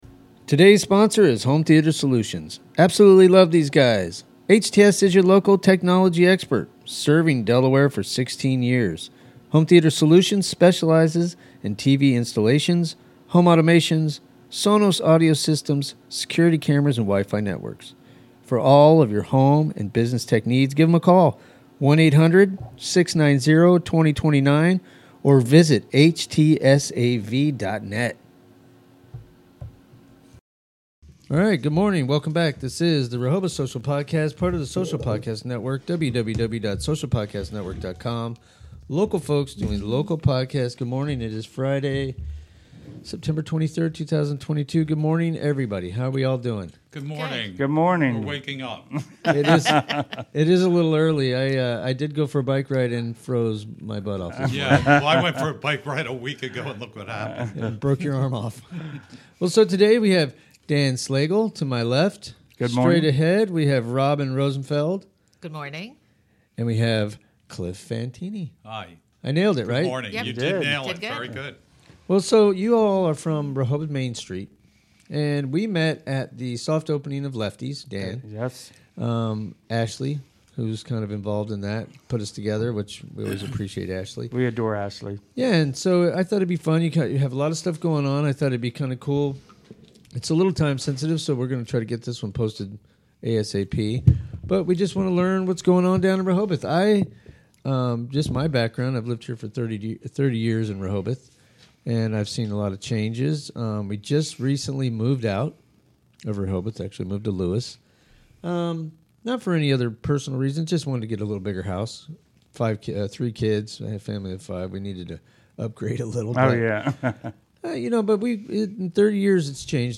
sit down and chat on upcoming events